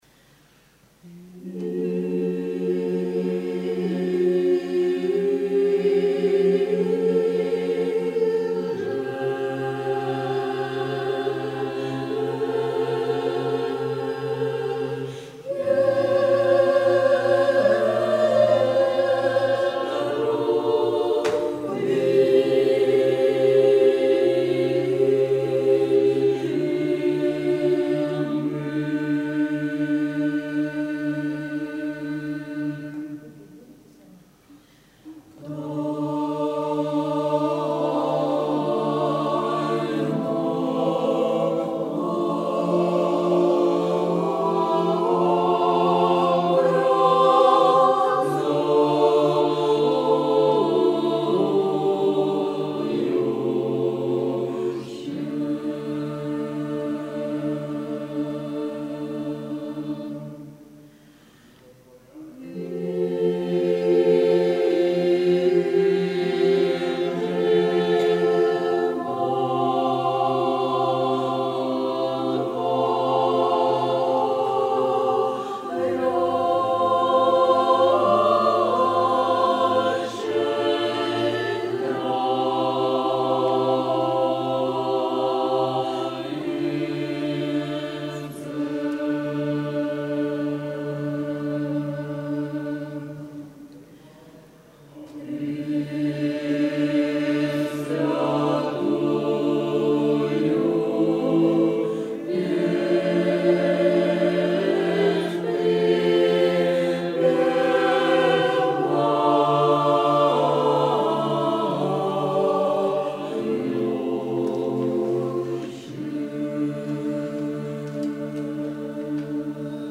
Church Slavonic translations for the choir of Andreevksii Kafedralnyi Sobor, Ust-Kamenogorsk, Kazakhstan
cherubic-hymn-slavonic.mp3